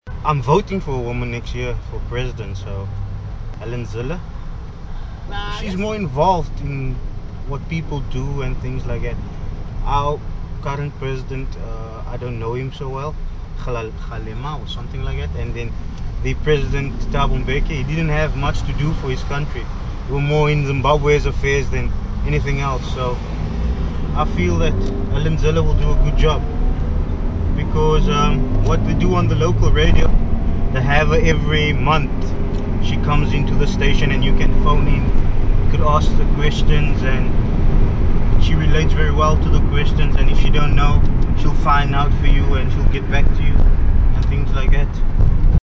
Taxi tales - Cape Town taxi drivers speak about feminism
During their stay, they spoke with taxi drivers about feminism, women in politics and violence against women.